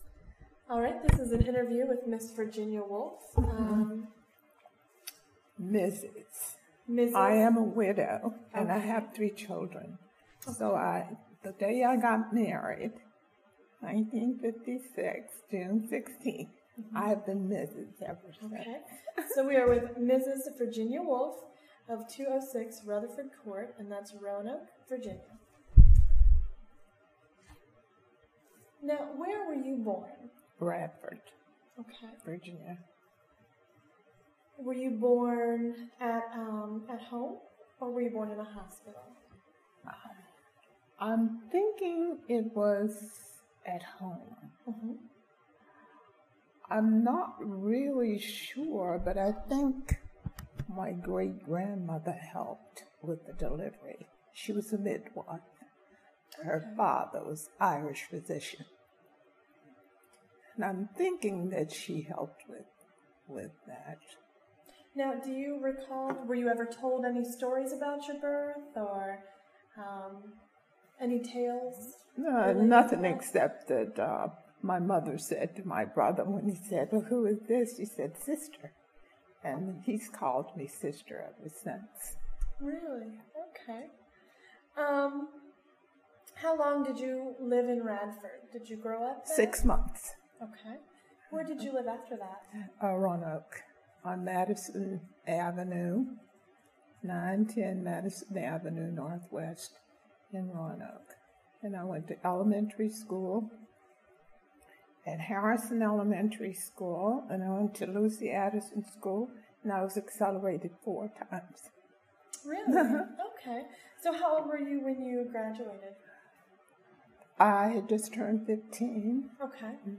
Location: Gainsboro Branch Library
Neighborhood Oral History Project